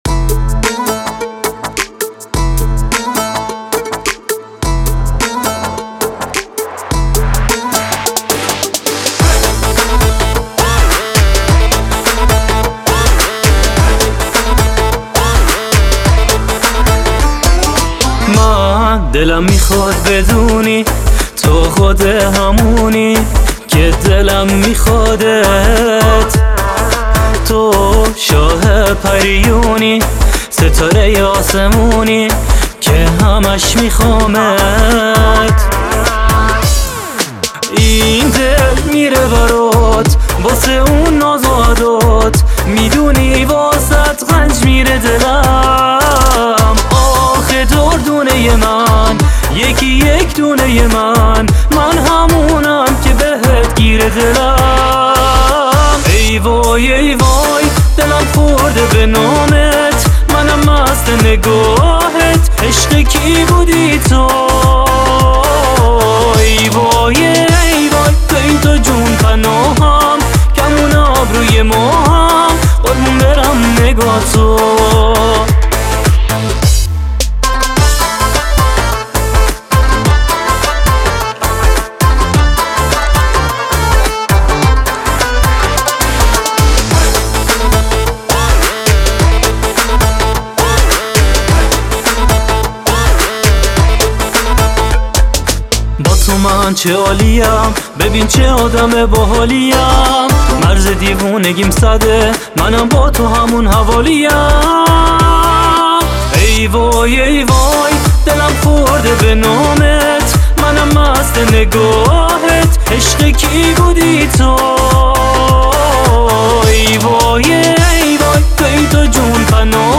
آهنگ افغانی